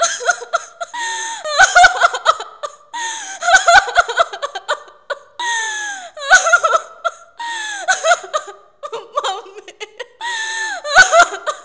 Here we host our database "IUEC (IIITD Urban Environment Context) database" which contains distress (scream and cry sounds) and sounds of 6 environmental contexts collected from mobile phones, movies and Internet.
Distress context     scream1.wav scream2.wav
cry1.wav